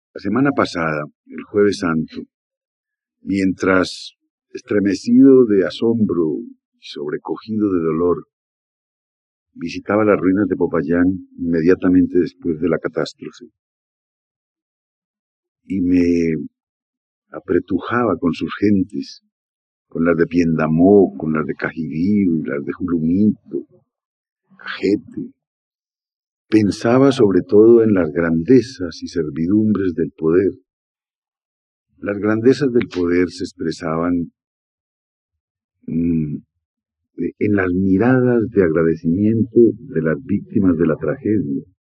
Alocución sobre el Terremoto de Popayán. Colombia, Radio Nacional.